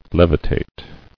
[lev·i·tate]